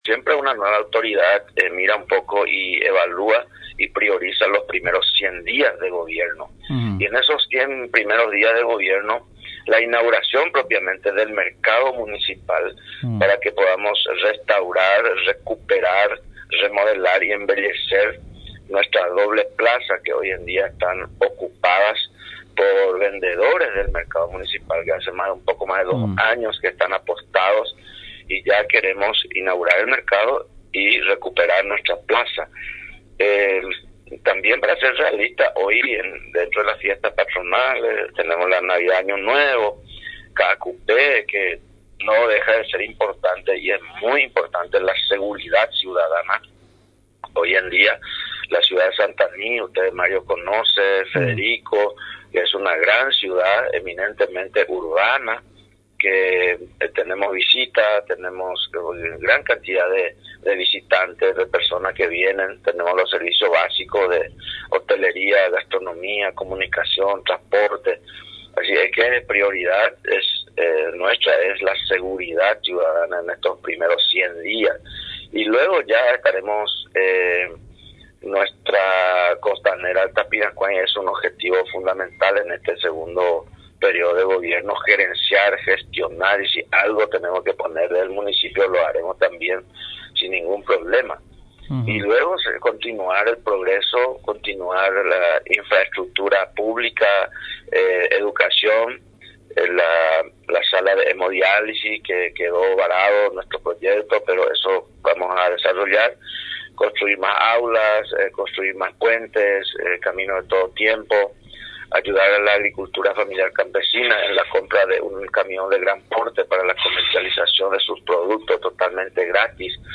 En la zona sur de San Estanislao, una de las ciudades de San Pedro que este fin de semana celebrará su aniversario de fundación realizó la ceremonia de juramento a los nuevos administradores y contralores del municipio, al respecto en comunicación con Radio Nacional San Pedro, el intendente reelecto licenciado Agustín Ovando, expresó que dentro sus primeros 100 días de gobierno priorizará la seguridad de la ciudad y la culminación del anhelado mercado municipal que beneficiará a miles de familias trabajadoras.